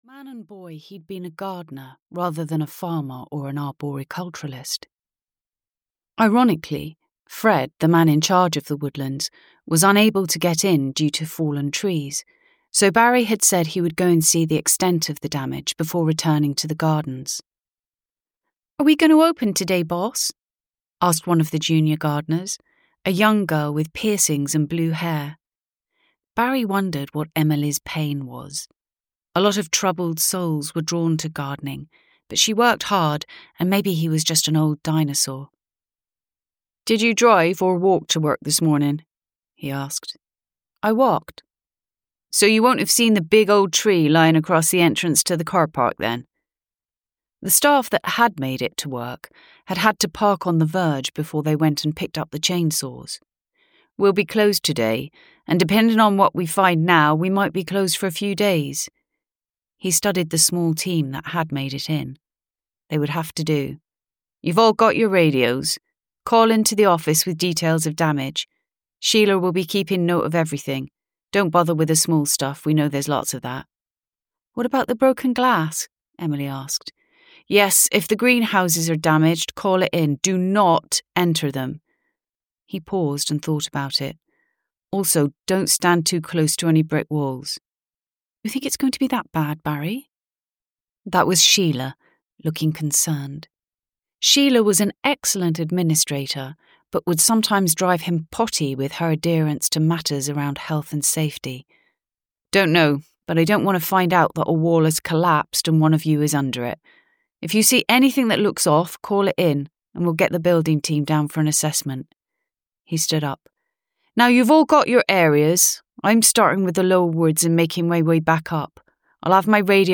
Ukázka z knihy
• InterpretNatasha Little